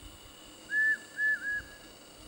Martineta Común (Eudromia elegans)
Nombre en inglés: Elegant Crested Tinamou
Localidad o área protegida: Amaicha del Valle
Condición: Silvestre
Certeza: Vocalización Grabada
martineta.mp3